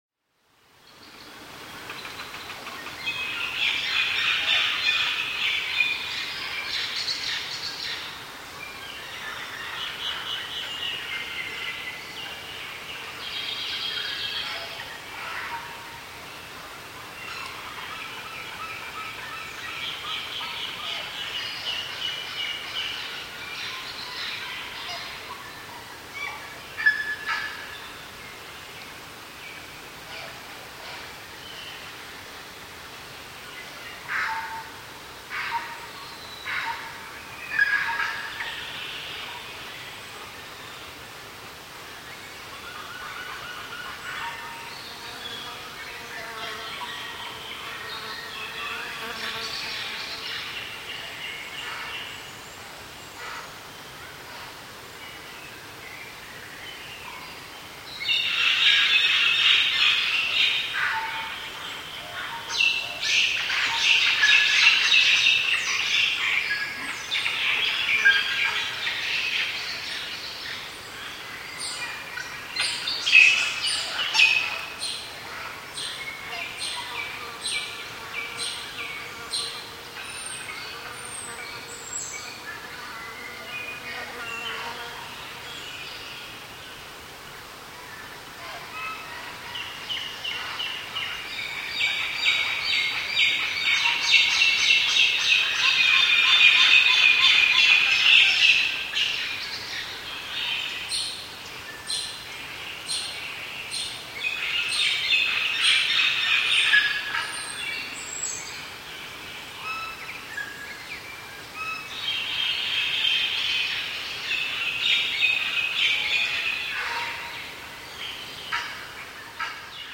Звуки леса -- НоваяЗеландия
• Категория: Звуки леса